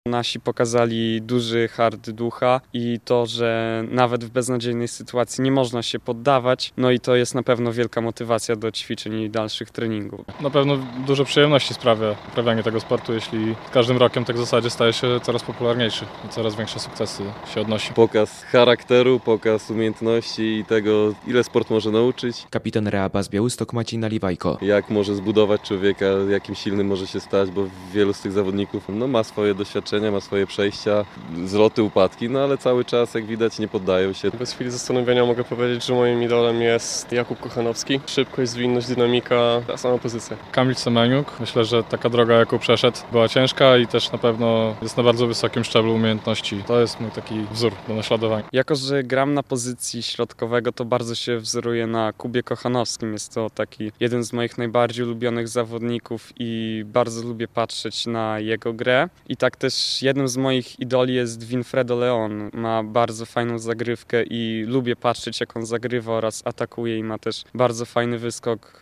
Siatkarze BAS-u Białystok o zwycięstwie polskich siatkarzy w półfinale igrzysk olimpijskich - relacja